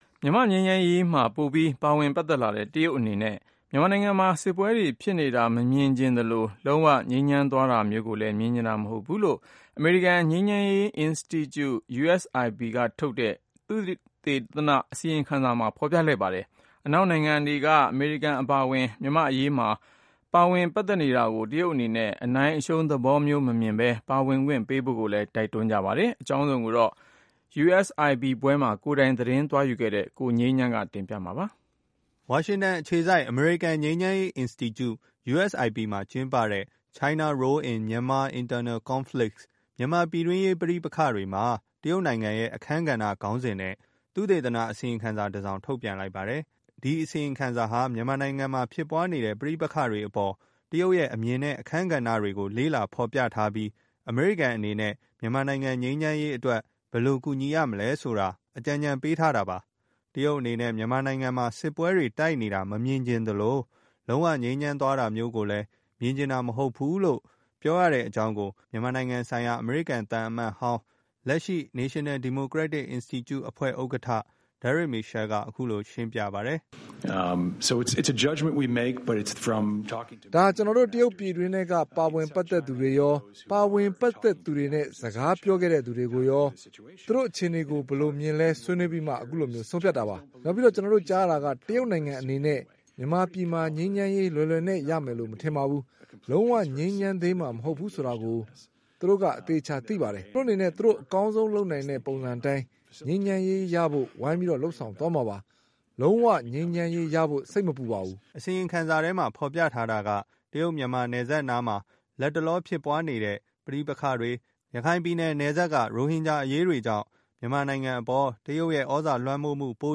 မြန်မာ့ ငြိမ်းချမ်းရေး လုပ်ငန်းစဉ်၊ နောက်ပြီးတော့ ရိုဟင်ဂျာ အရေး ကိစ္စတွေမှာ မြန်မာအစိုးရဘက် က အစဉ်တစိုက်ကာကွယ်ပေးနေတဲ့ တရုတ်နိုင်ငံဟာ မြန်မာ့ပြည်တွင်းရေးမှာ အဓိက နေရာက ပါဝင်လာနေတာဖြစ်ပါတယ်။ အခုအစီရင်ခံစာ က အကြံပြုထားချက်တွေဟာ ကျိုးကြောင်းဆီလျော်ကြောင်း အမေရိကန်နိုင်ငံဆိုင်ရာ မြန်မာသံအမတ်ကြီး ဦးအောင်လင်းက အခုလို ပြောပါတယ်။